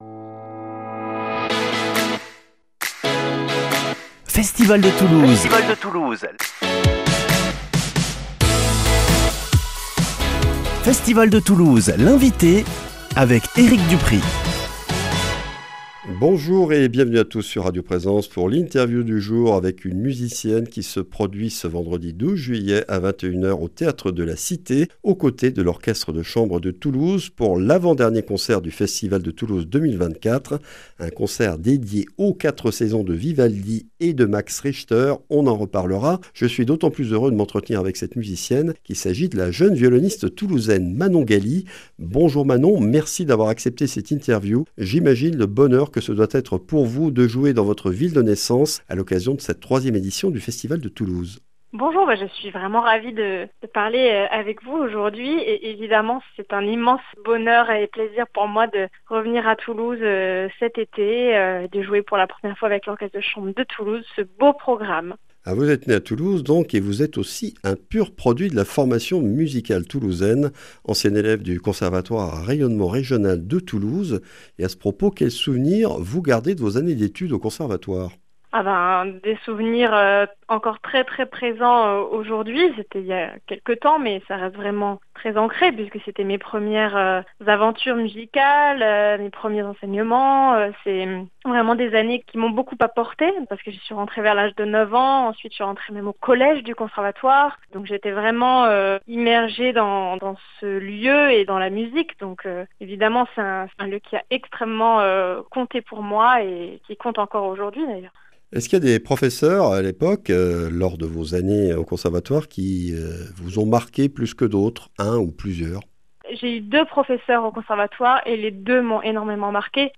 Festival de Toulouse 2024 - ITW